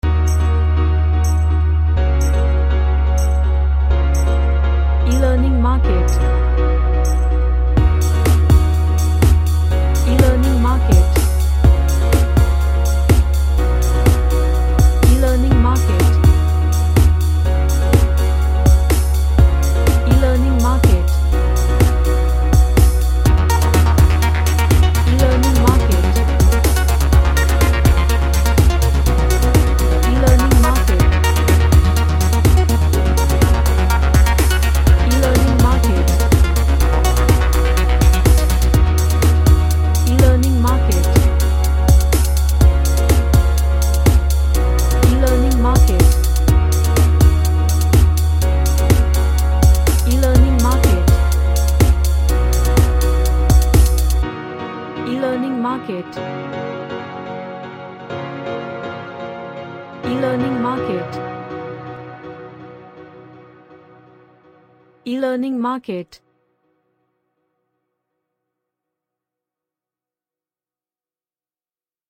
A nice chordal vibed electronic synth track.
Uplifting